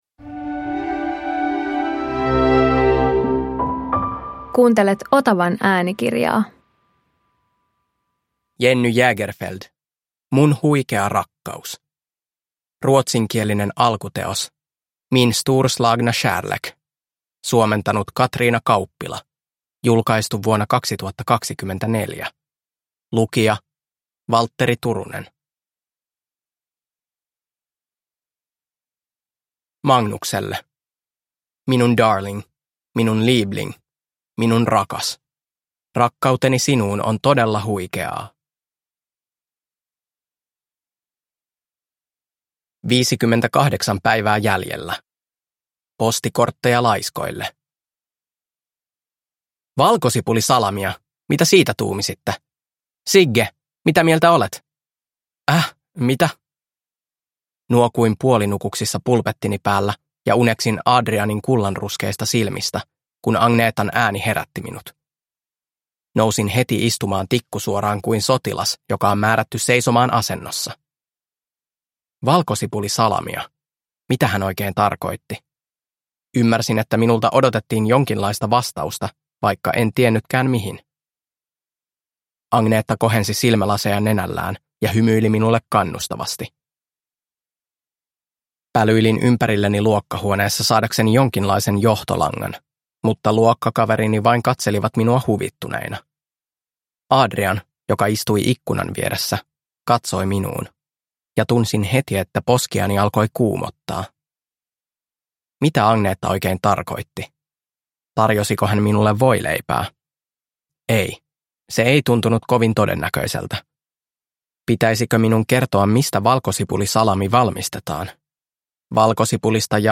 Mun huikea rakkaus – Ljudbok